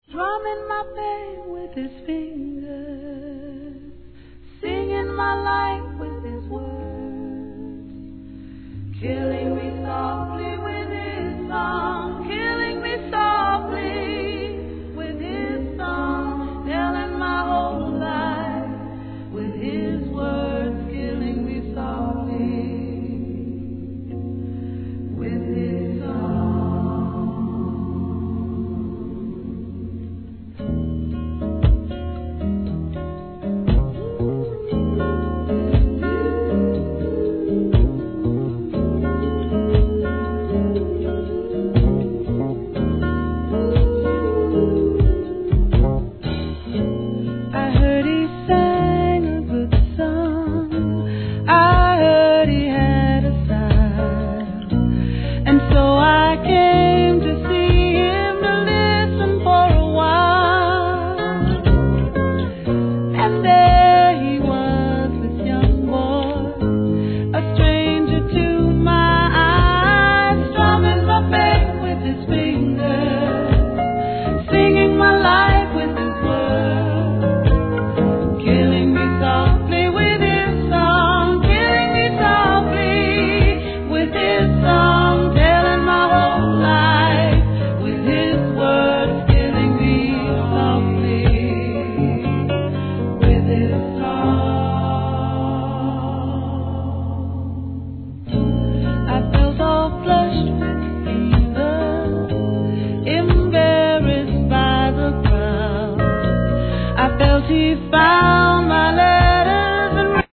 ¥ 880 税込 関連カテゴリ SOUL/FUNK/etc...